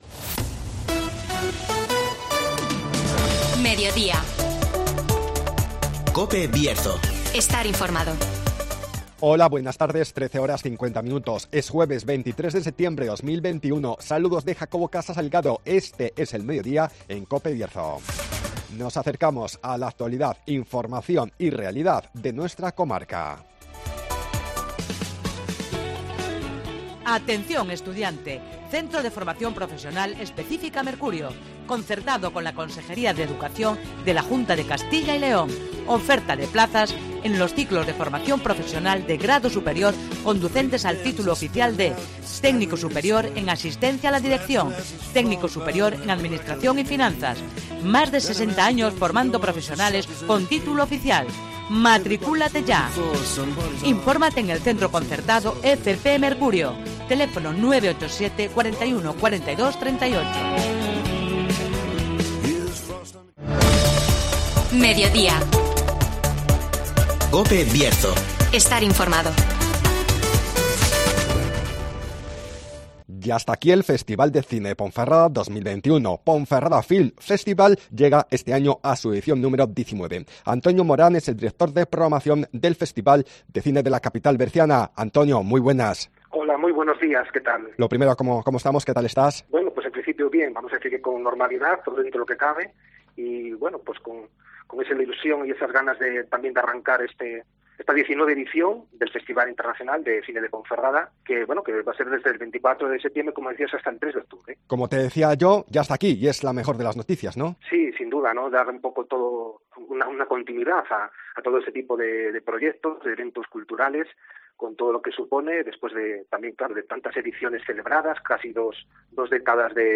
Ya está aquí la edición 19 del Festival de Cine de Ponferrada (Entrevista